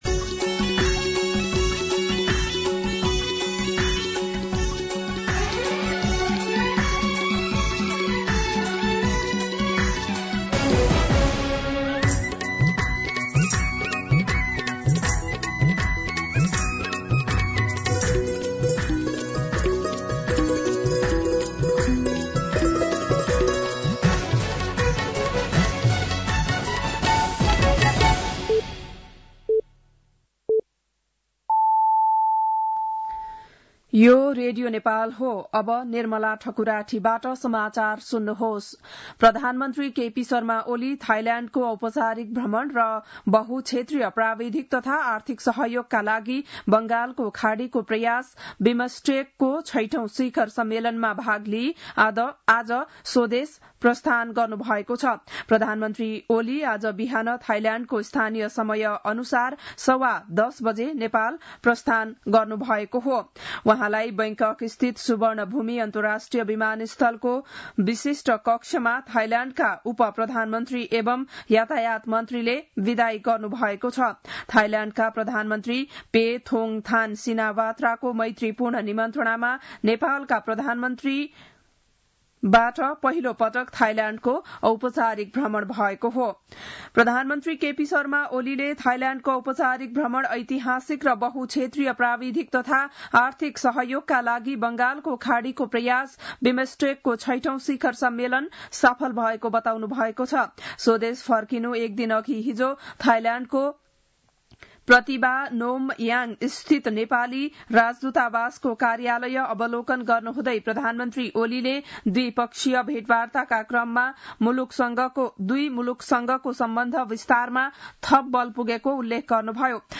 बिहान ११ बजेको नेपाली समाचार : २३ चैत , २०८१
11-am-Nepali-News-1.mp3